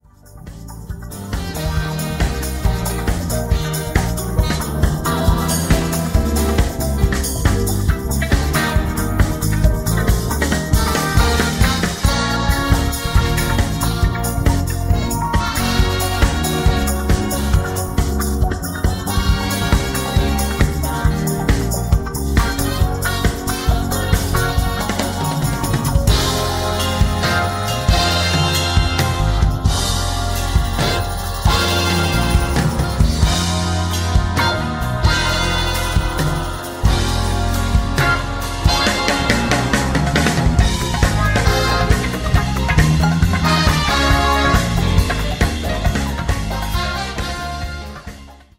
version live